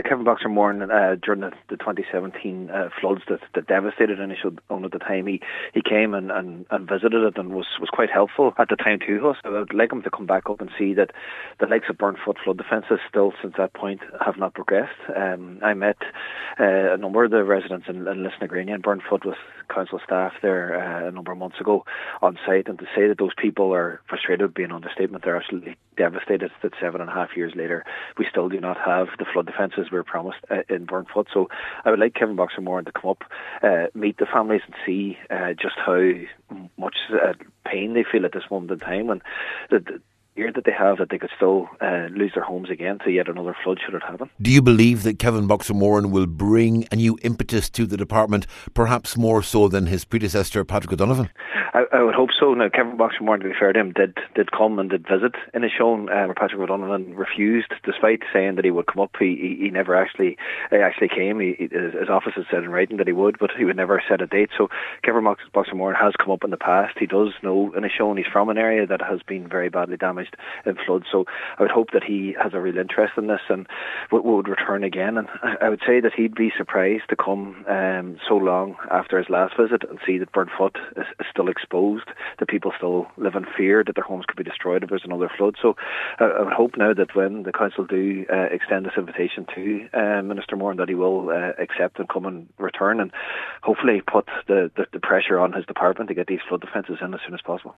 MD Cathaoirleach Jack Murray expects he’ll be disappointed at the lack of progress…………..